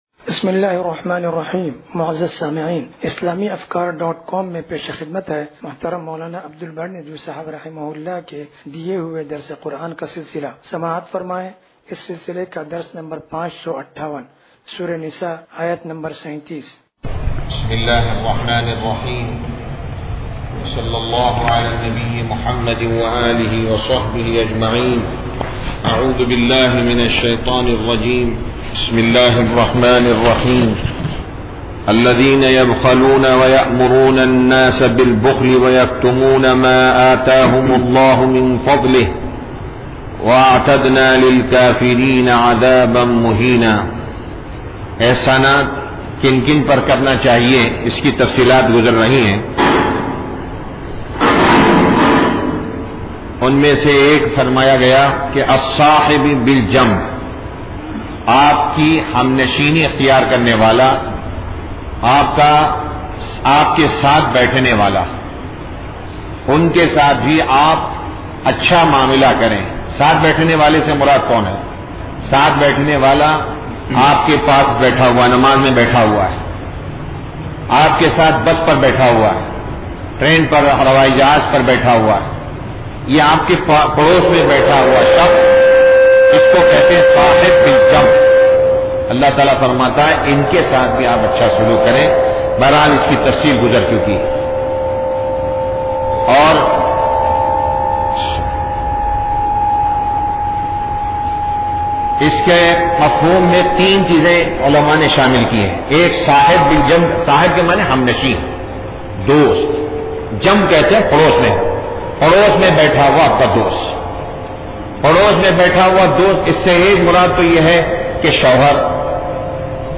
درس قرآن نمبر 0558
درس-قرآن-نمبر-0558.mp3